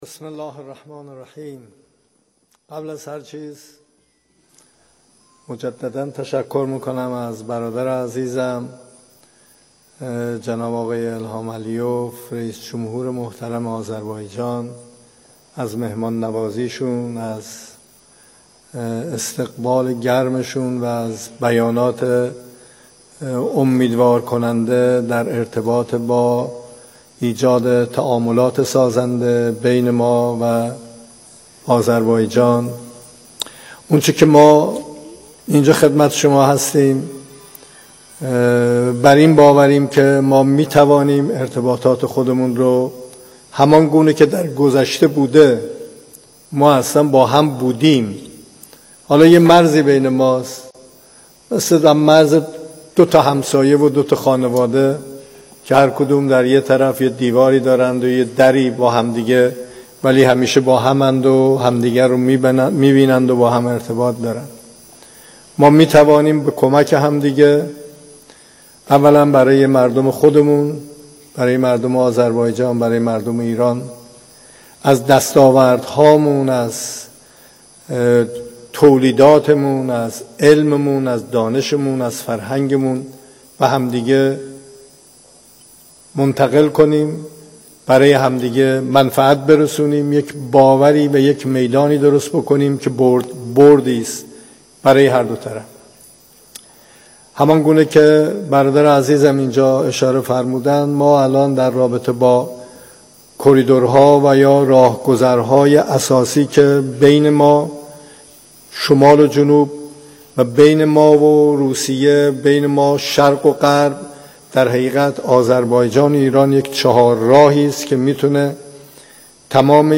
سخنان رئیس جمهور در نشست با تجار و بازرگانان ایران و جمهوری آذربایجان